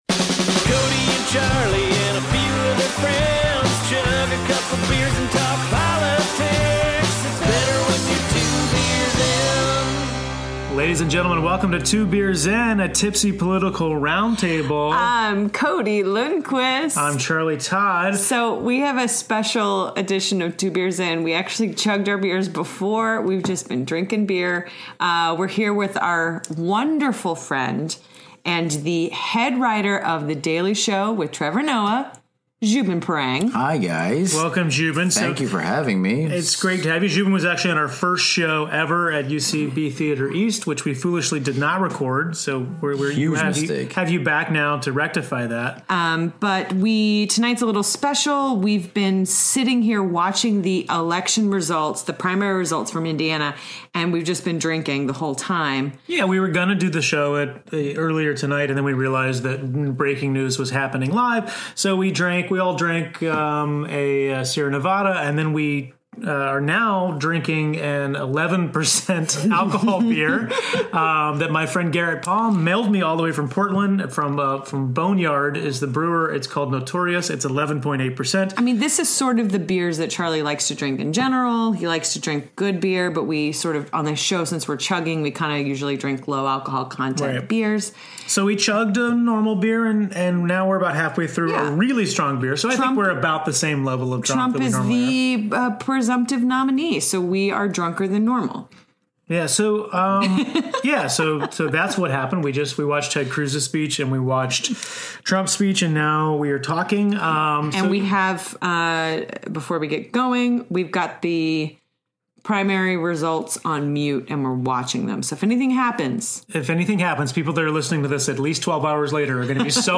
Recorded in our Hell's Kitchen apartment on May 4, 2016.